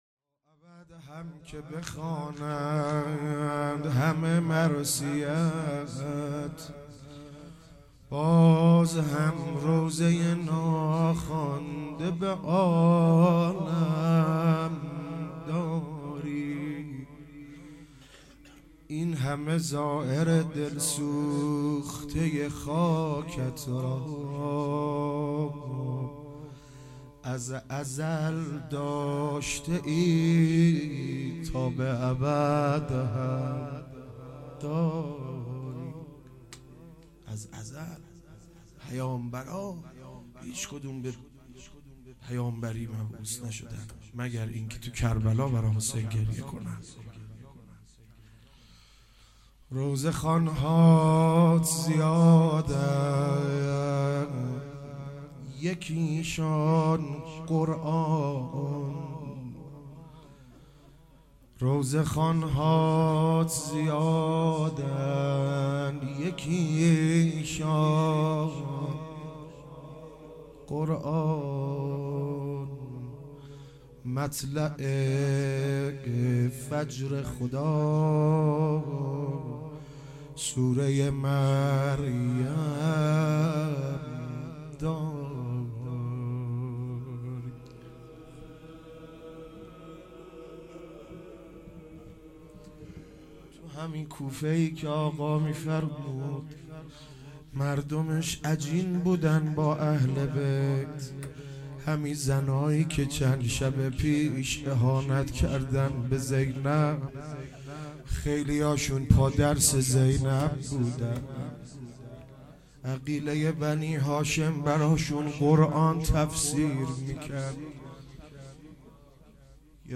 روضه - و بعد هم که بخوانند همه مرثیه ات